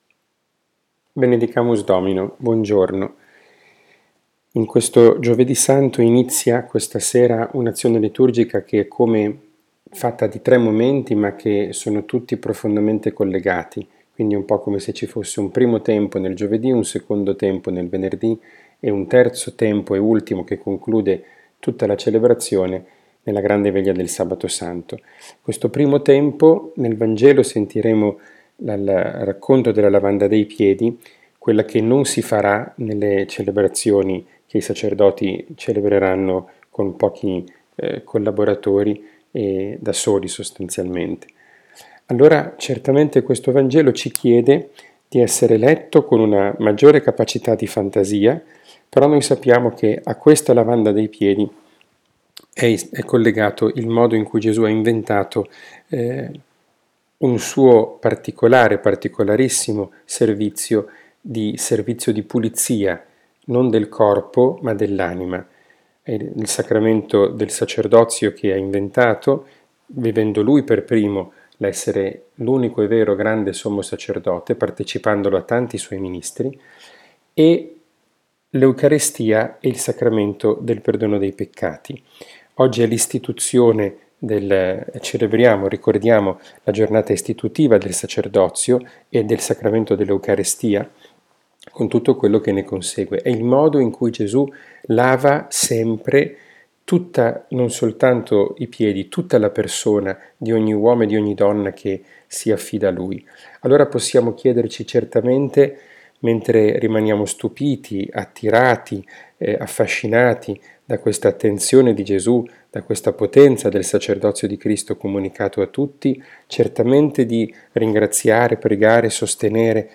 catechesi, Parola di Dio, podcast